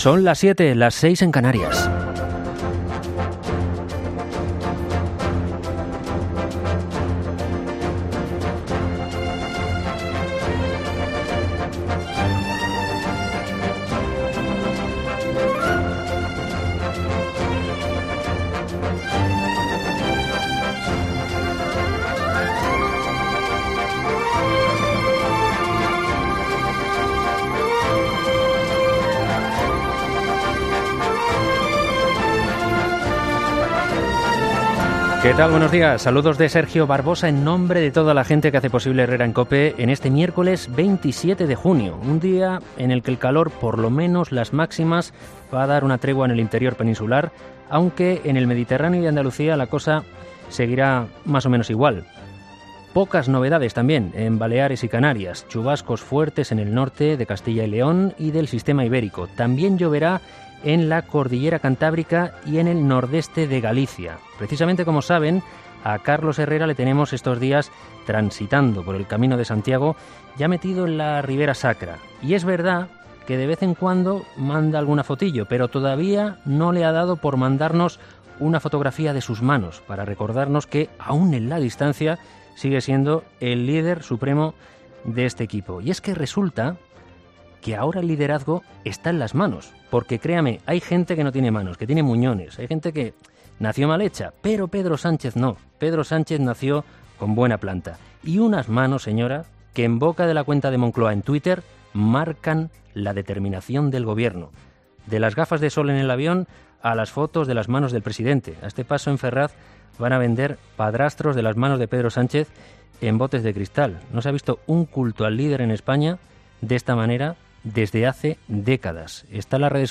Monólogo